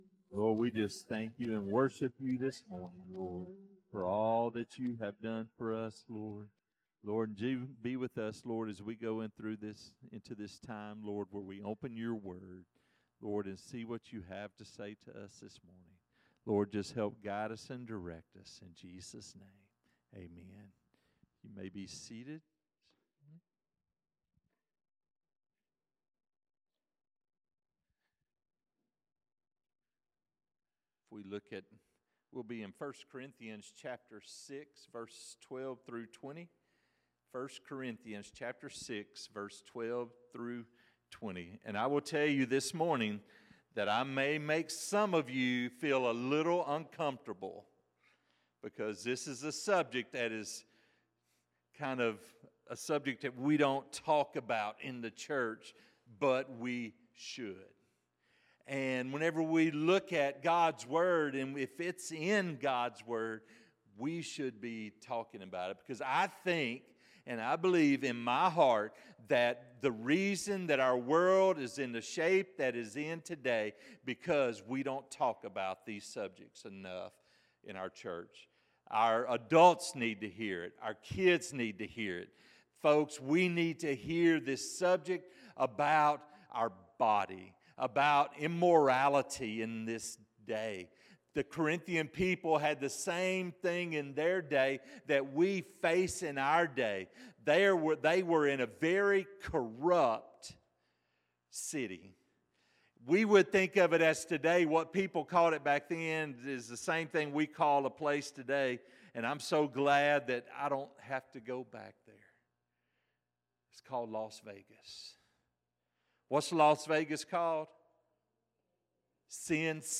Sermons | First Southern Baptist Church Bearden